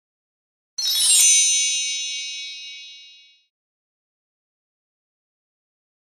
دانلود صدای چوب جادو و شعبده بازی 3 از ساعد نیوز با لینک مستقیم و کیفیت بالا
جلوه های صوتی